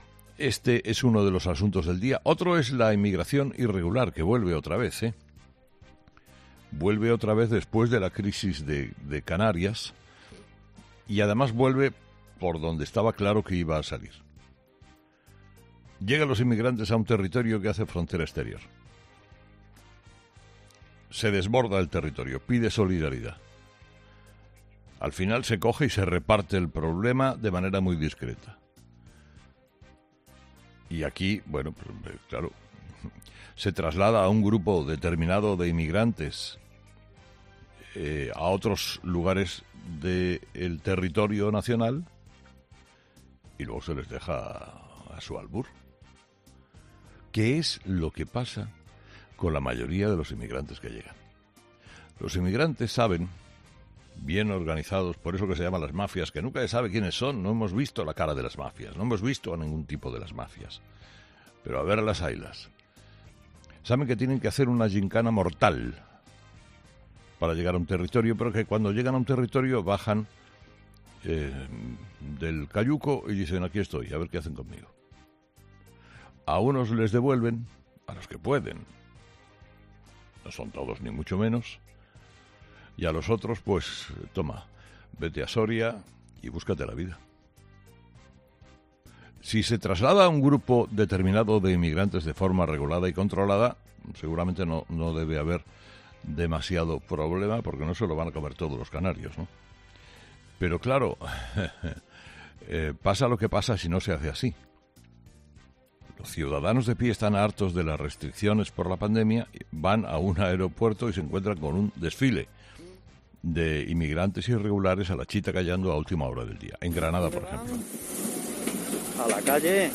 Carlos Herrera, director y presentador de 'Herrera en COPE', analiza la última polémica relacionada con los problemas de la inmigración ilegal en nuestro país